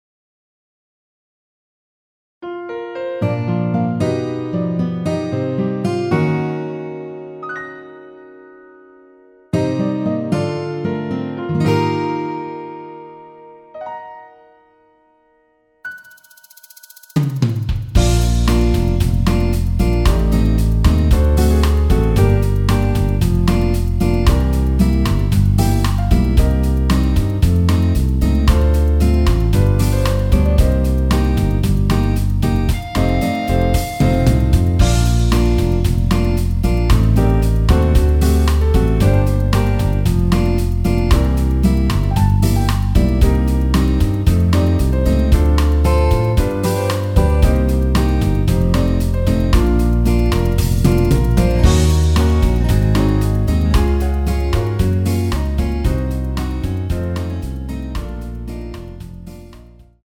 Bb
앞부분30초, 뒷부분30초씩 편집해서 올려 드리고 있습니다.
중간에 음이 끈어지고 다시 나오는 이유는